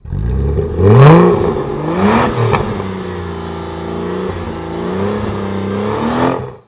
motore3.wav